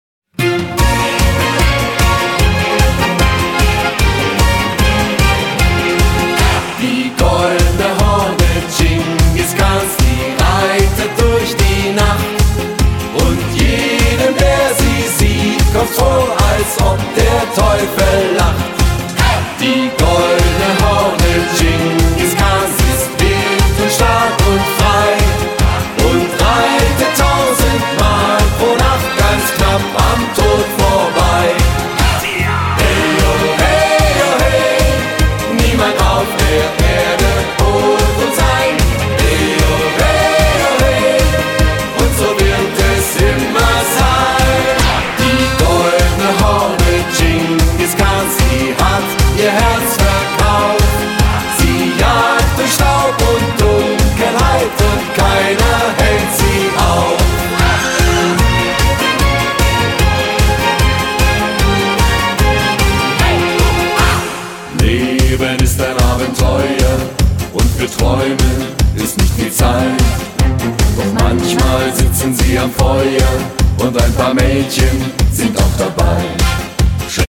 Disco-Pop